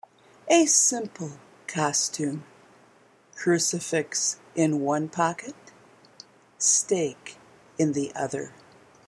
2009 Halloween Poetry Reading